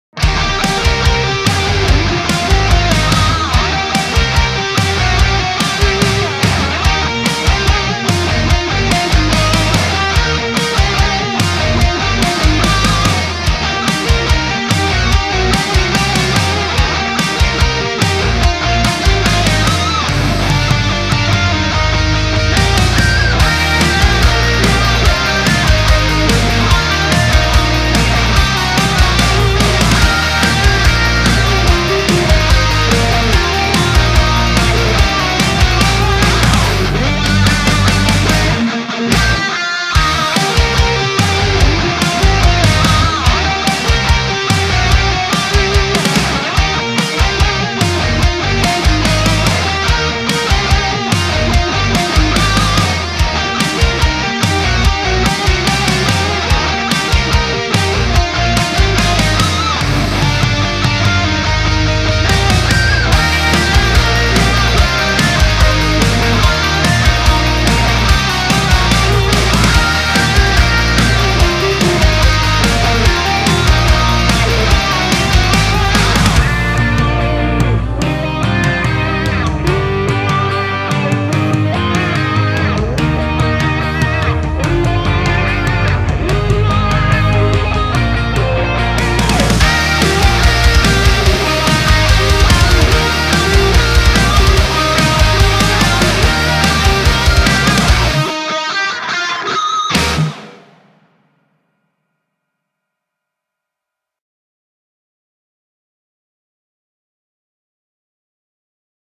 Metal_Rock Guitar Cover Remix